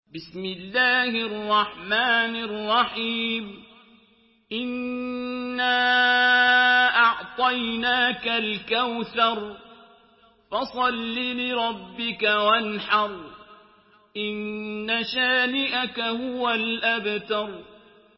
Surah Al-Kawthar MP3 by Abdul Basit Abd Alsamad in Hafs An Asim narration.
Murattal